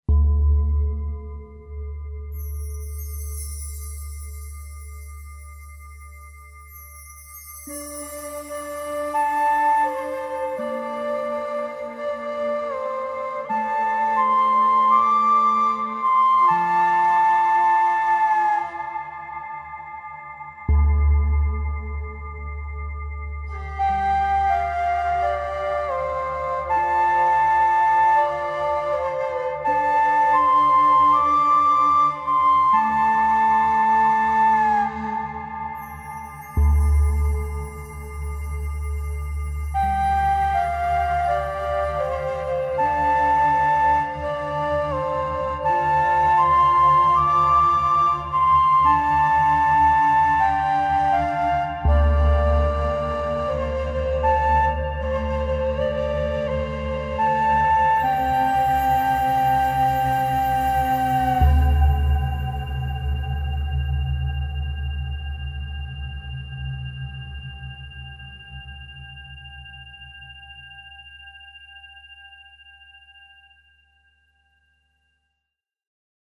A Staggering collection of filmic moods and themes